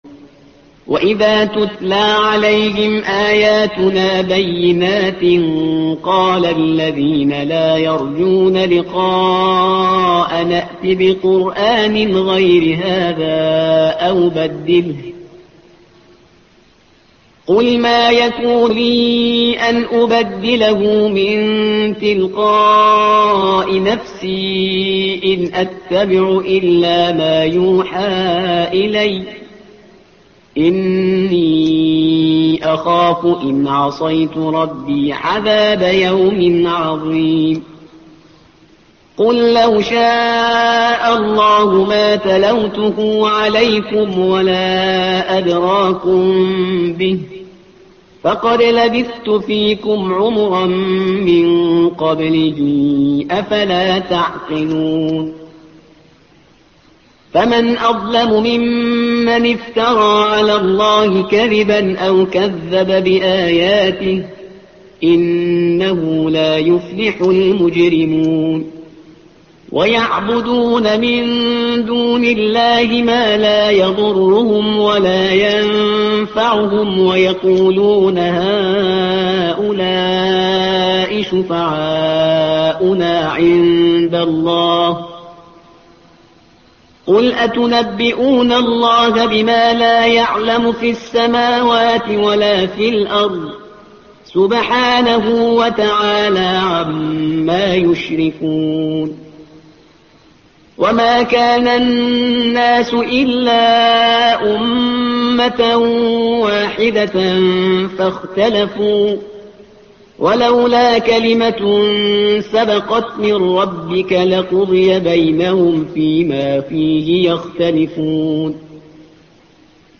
الصفحة رقم 210 / القارئ